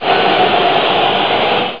1 channel
F-JEER.mp3